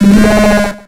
Cri d'Écrémeuh dans Pokémon X et Y.